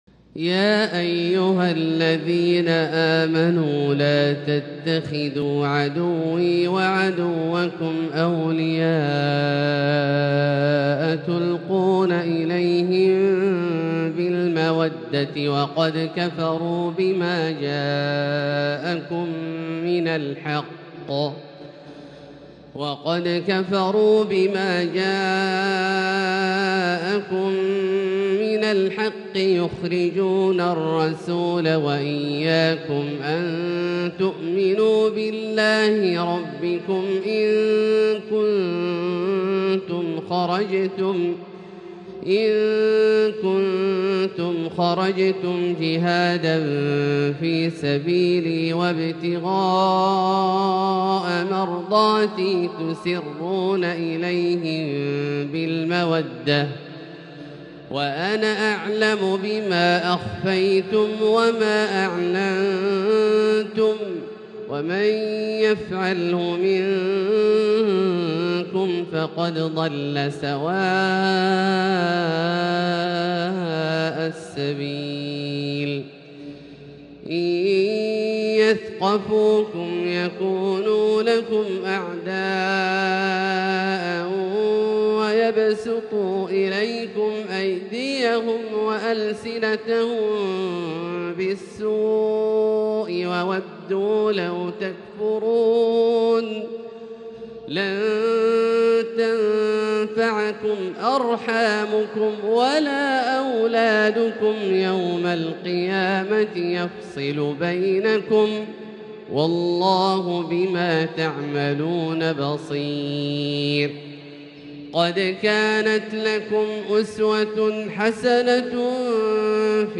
تلاوة خاشعة لـ سورة الممتحنة كاملة للشيخ د. عبدالله الجهني من المسجد الحرام | Surat Al-Mumtahinah > تصوير مرئي للسور الكاملة من المسجد الحرام 🕋 > المزيد - تلاوات عبدالله الجهني